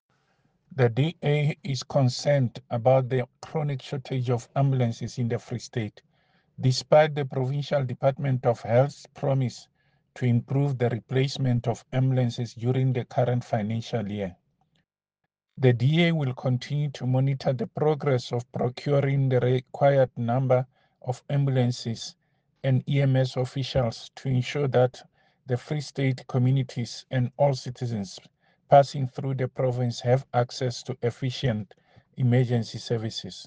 Sesotho soundbites by David Masoeu MPL.